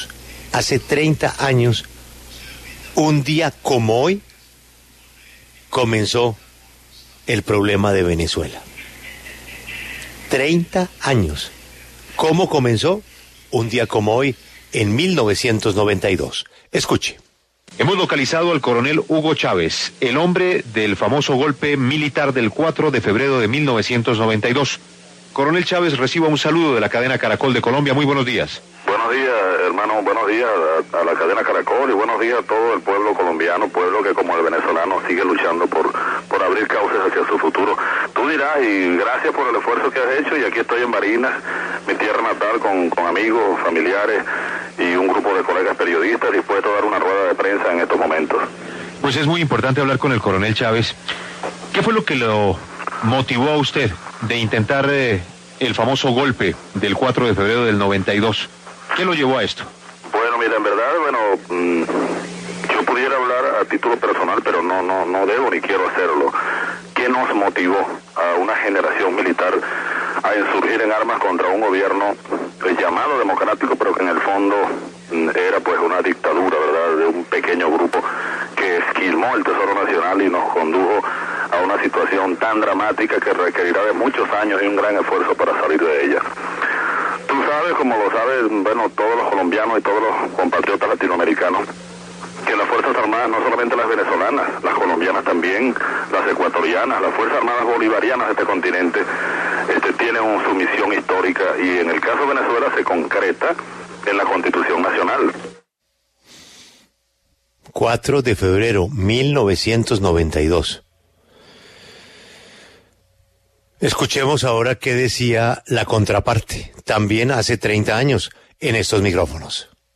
La W reveló conversaciones con el fallecido Hugo Chávez y el expresidente de Venezuela Carlos Andrés Pérez sobre el intento de golpe de Estado del 4 de febrero de 1992.
En diálogo con Julio Sánchez Cristo narraron lo que ocurrió en esa ocasión